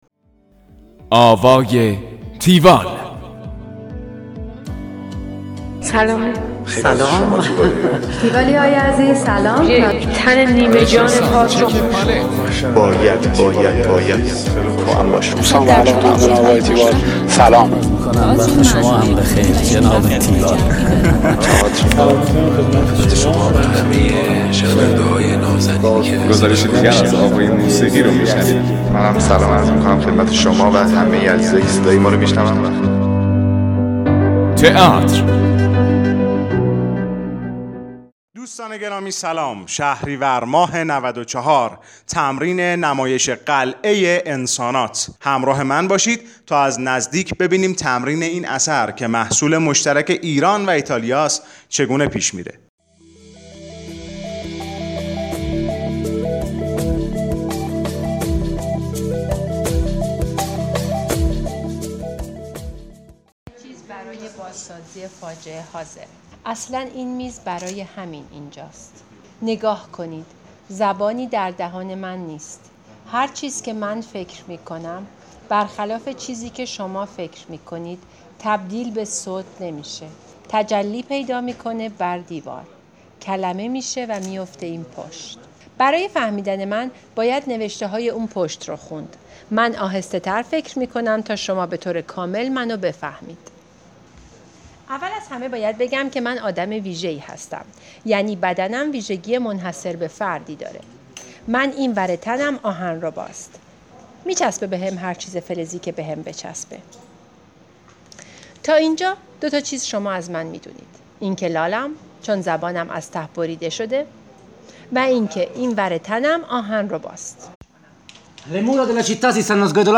گزارش آوای تیوال از نمایش قلعه انسانات